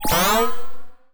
sci-fi_power_up_06.wav